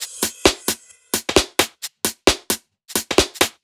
Index of /musicradar/uk-garage-samples/132bpm Lines n Loops/Beats